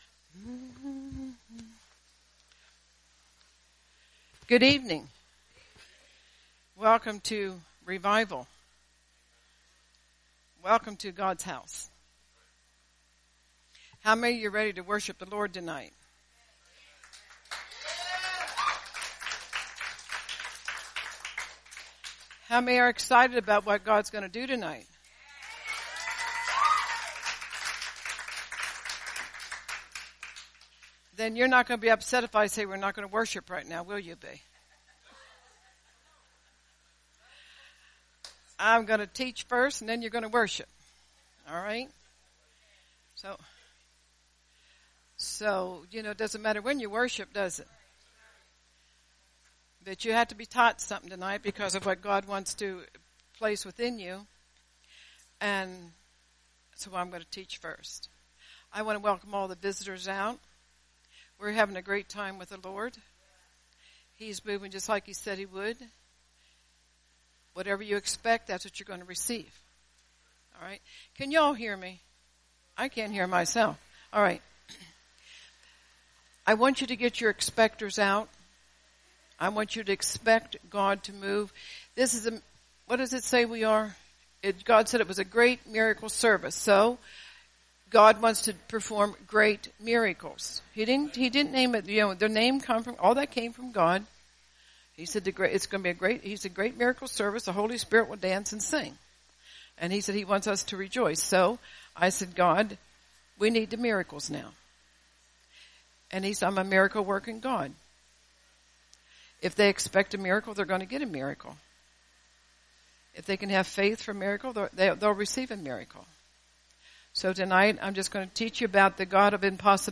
Great Miracle Service Revival